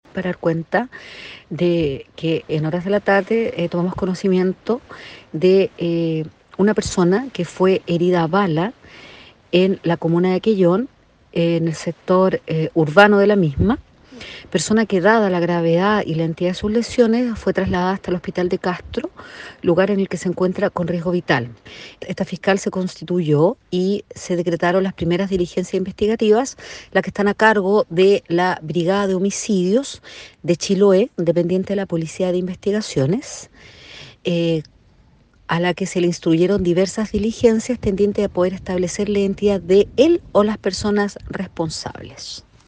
Acerca del acontecimiento policial informó la fiscal de la comuna de Quellón, Karyn Alegría, quien en primer término confirmó la gravedad de las lesiones sufridas por esta víctima, la que está con riesgo vital, por lo que hubo necesidad de evacuarlo de urgencia al Hospital de Castro.
01-FISCAL-KARYN-ALEGRIA-POR-DISPAROS.mp3